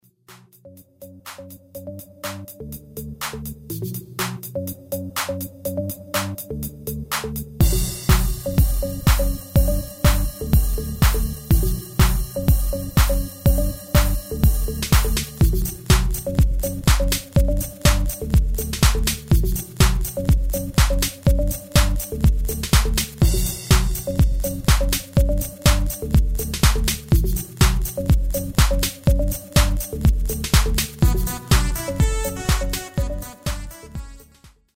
Genre: Disco
- Géén vocal harmony tracks
Demo = Demo midifile